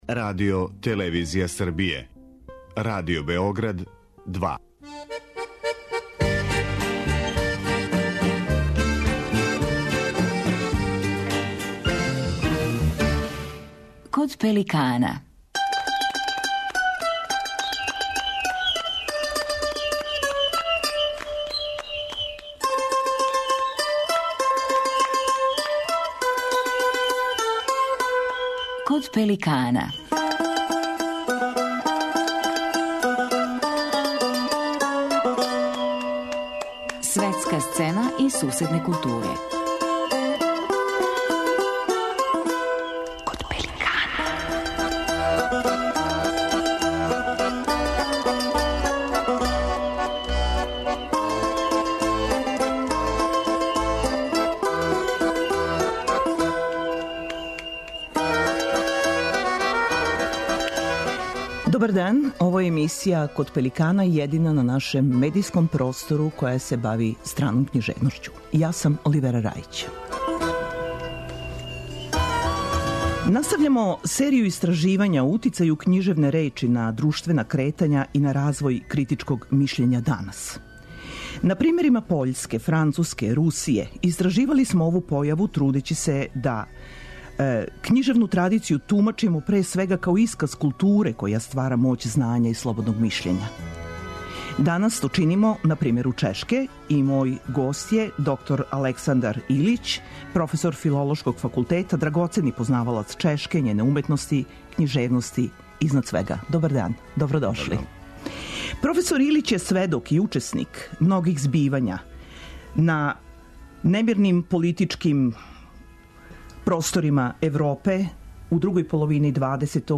У данашњем издању емисије слушаћемо др Александра Илића, проф. Филолошког факултета, некадашњег амбасадора у Чешкој, једног од оснивача Демократске странке и познатог борца за слободу јавне речи и за критичко мишљење.